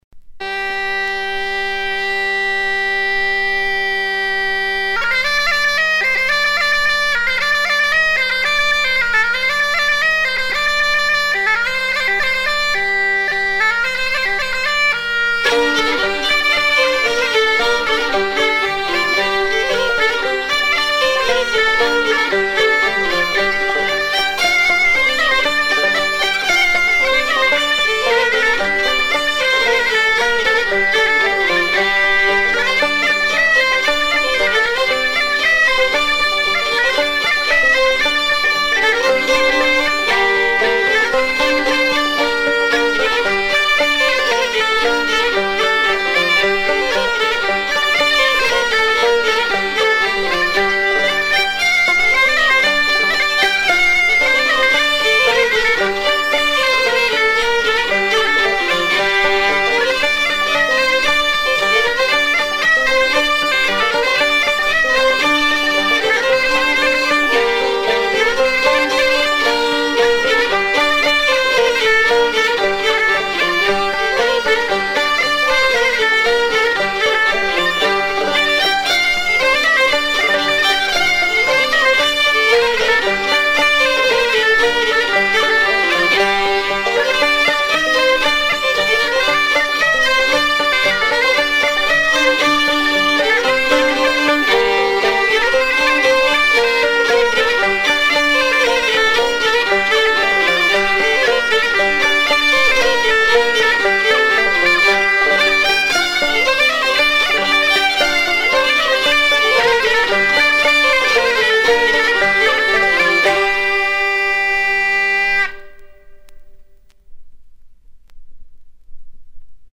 danse : matelote